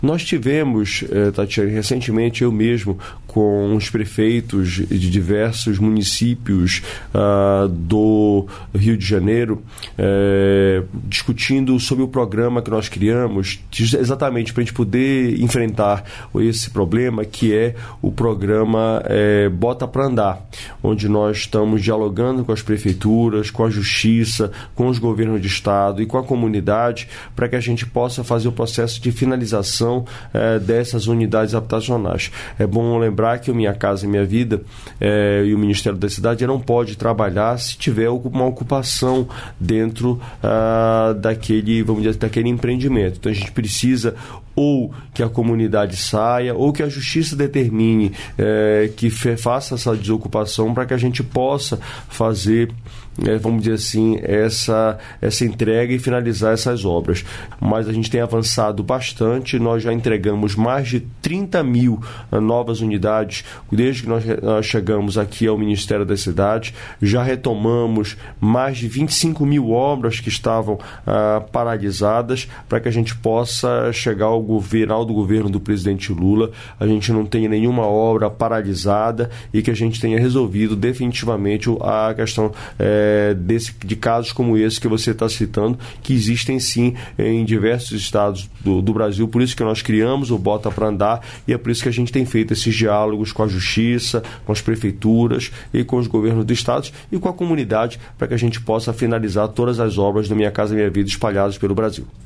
Trecho da participação do ministro das Relações Exteriores, Mauro Vieira, no programa Bom Dia, Ministro desta quarta-feira (27), nos estúdios da EBC, em Brasília.